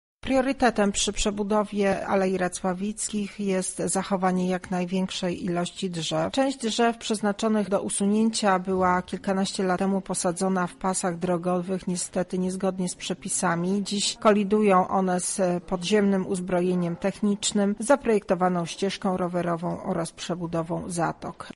O szczegółach mówi